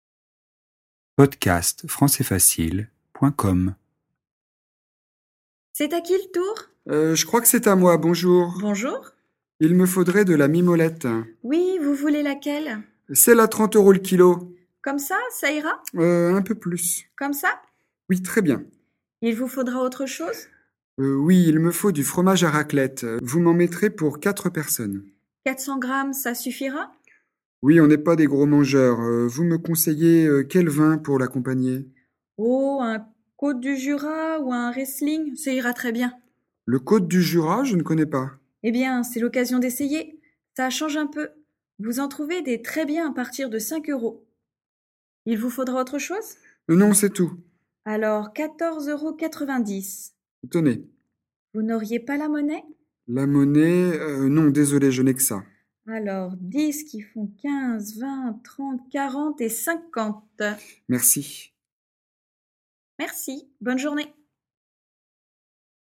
Situation : Sur le marché, une cliente demande conseille à la fromagère.
DIALOGUE :